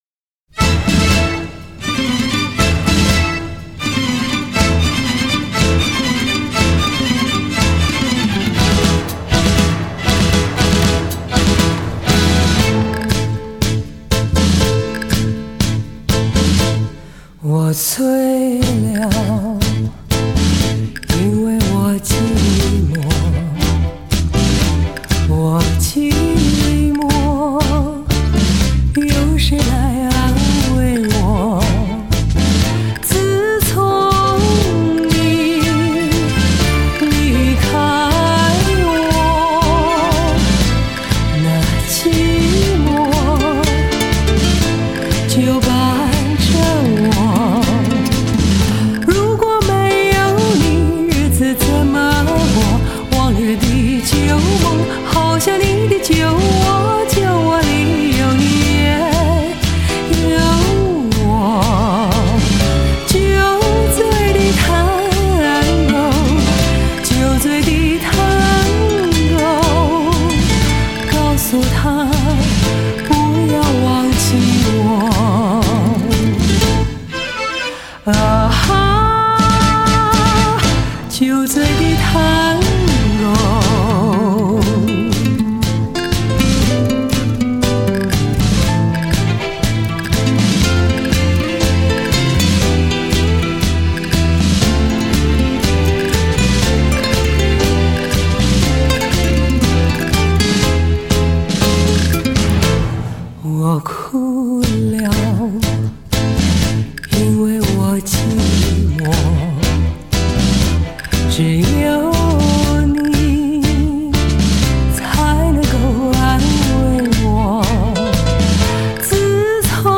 诠释探戈 恰恰 吉鲁巴等舞曲 挑动每根爱跳舞的神经
探戈 Tango